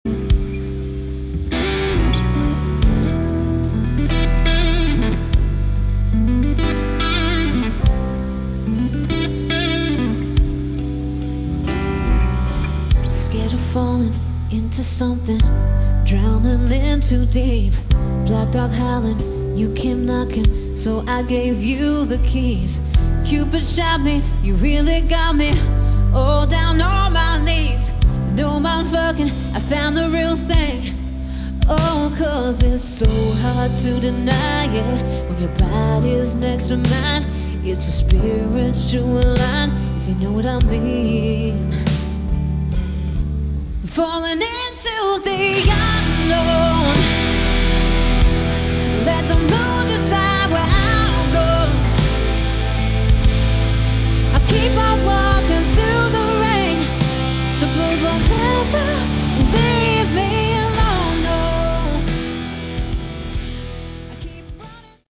Category: Hard Rock
guitar, vocals
bass
keyboards
drums